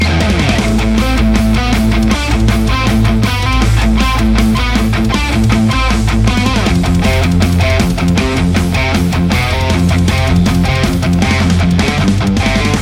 The M1 Epona pack includes captures ranging from clean to full metal hi gain and everything in between plus my personal YouTube IR that I use in my demos are also included.
Metal Riff Mix
RAW AUDIO CLIPS ONLY, NO POST-PROCESSING EFFECTS